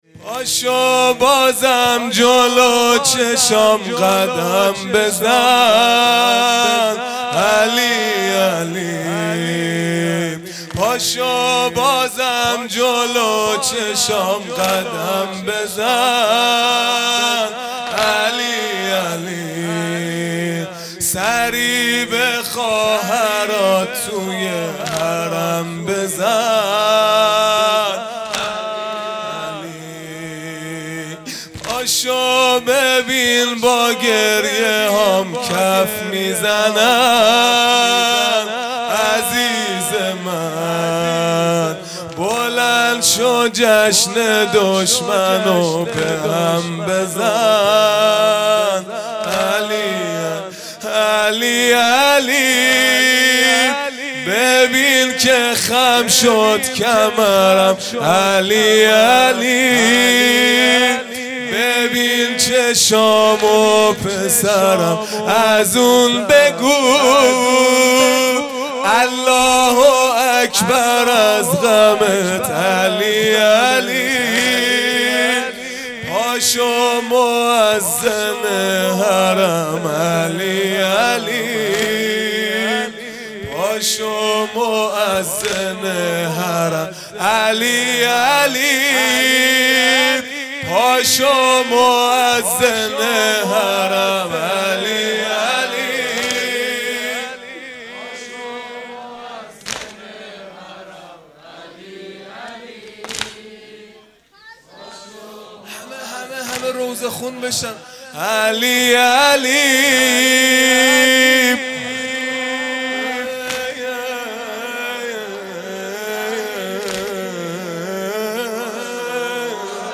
دهه اول محرم الحرام ۱۴۴۳ | شب هشتم | دوشنبه ۲۵ مرداد ۱۴۰۰